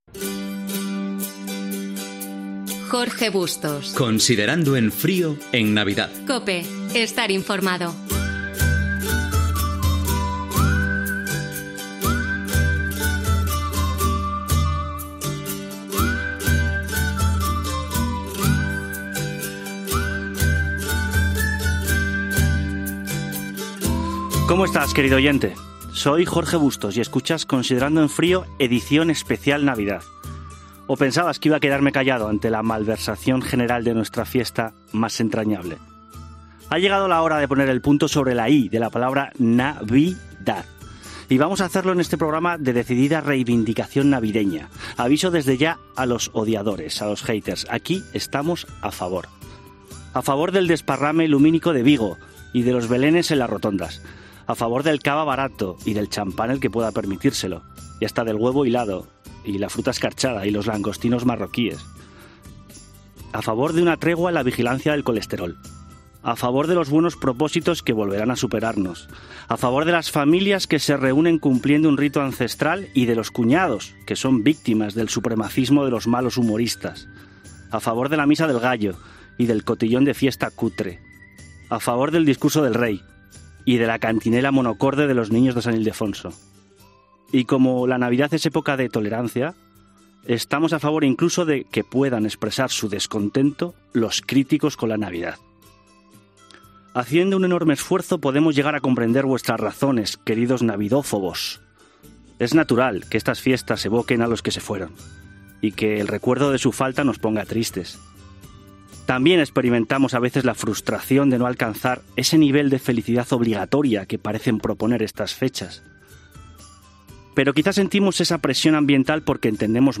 Especial nadalenc. Careta del programa, monòleg dedicat a la reivindicació del Nadal. Fragment de la tertúlia